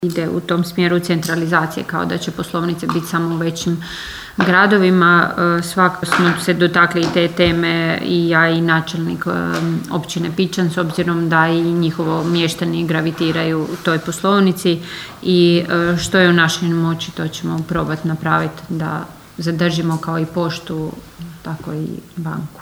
Na sjednici Općinskog vijeća Kršana raspravljalo se o najavi zatvaranja poslovnice Erste banke u Potpićnu.
„Načula sam da…“, odgovorila je načelnica Ana Vuksan: (